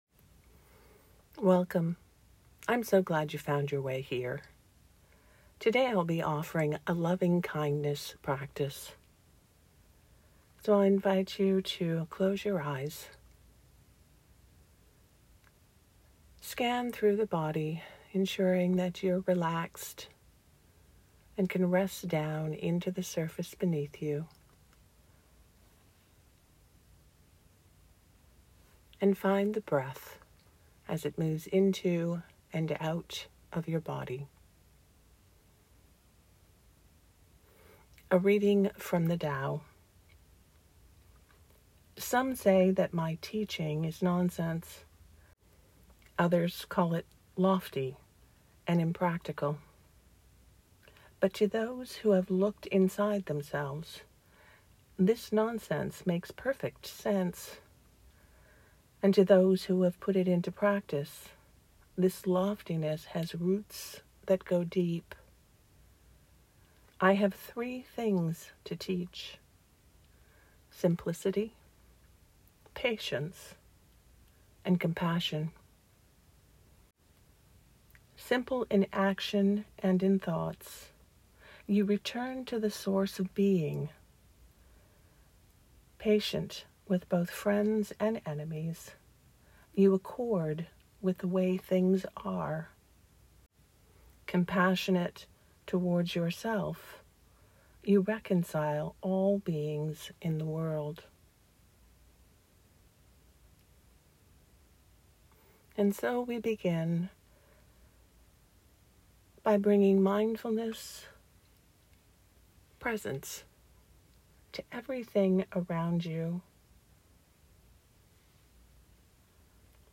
These meditations are offered freely.